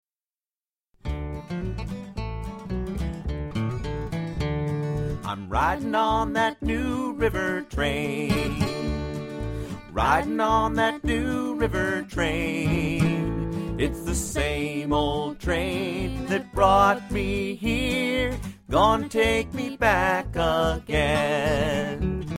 Demo MP3